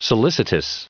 added pronounciation and merriam webster audio
677_solicitous.ogg